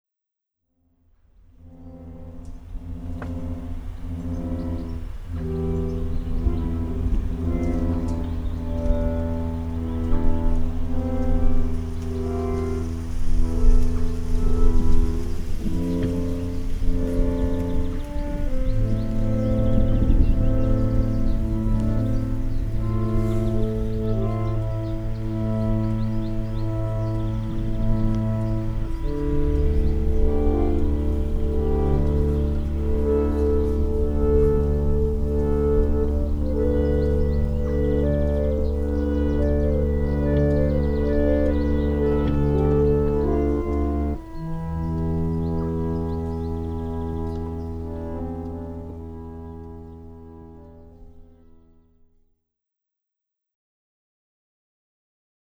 KÄLLSTAD - landscape score If you go to the church of Källstad you get the most splendid view of the lake Tåkern. An organ is placed outside between the graves so you can interpret the landscape in a free manner and transform it into soundscape. The concrete sounds of swedish summer will accompany.